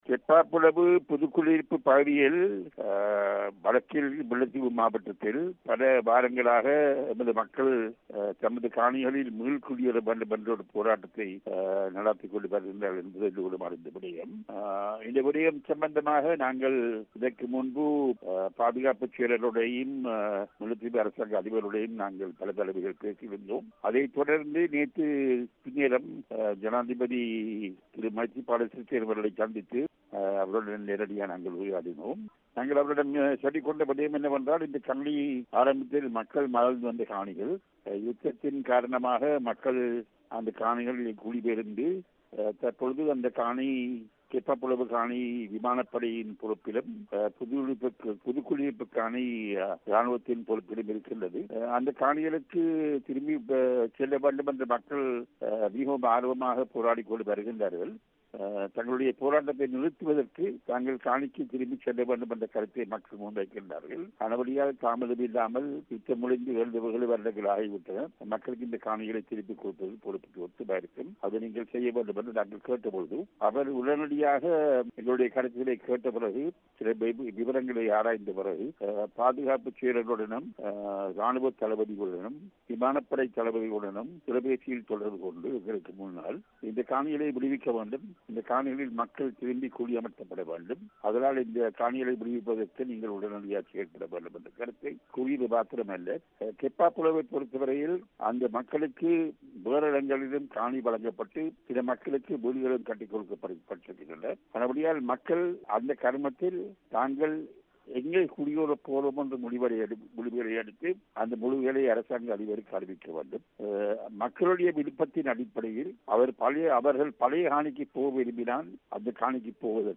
கேப்பாப்பிலவு காணிகள் விடுவிப்பு தொடர்பாக ஜனாதிபதியிடம் எடுத்துரைத்தது என்ன? இரா. சம்பந்தன் பேட்டி